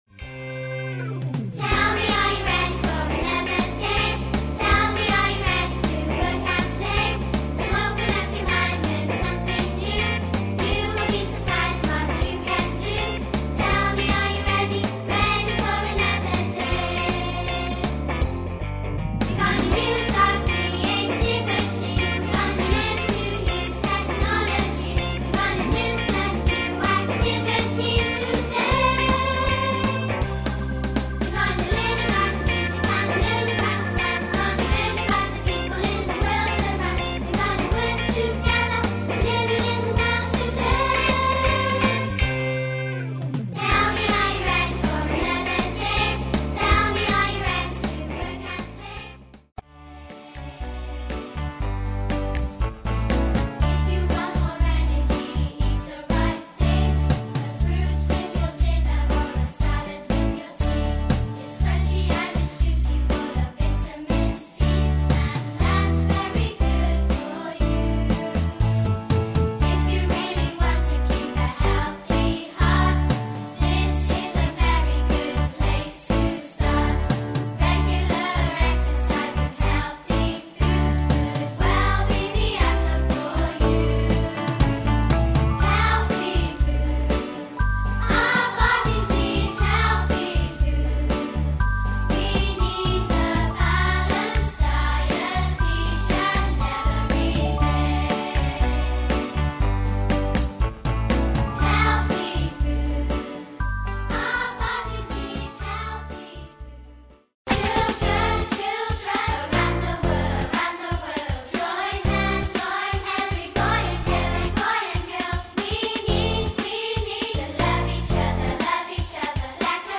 A collection of 15 new songs for schools.